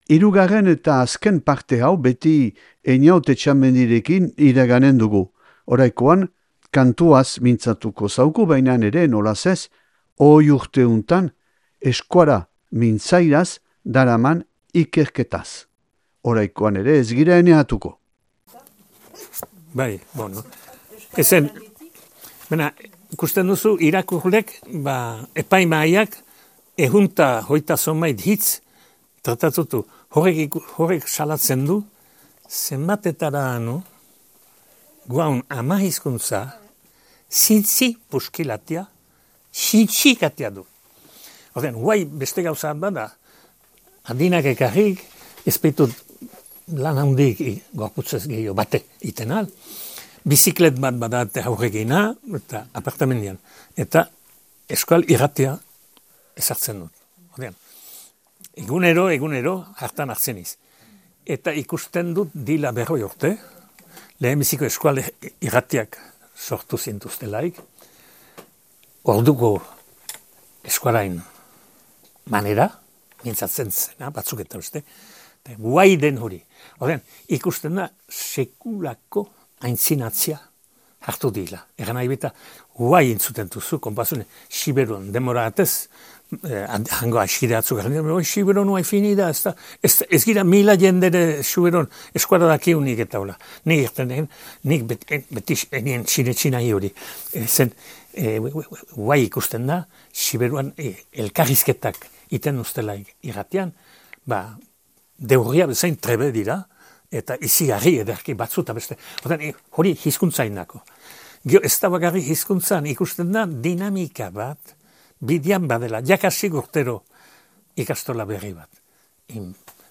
Aldude Baigorri Ortzaizeko irakurle taldeak antolaturik Ortzaizeko Menta liburutegian 2024. azaroaren 14an.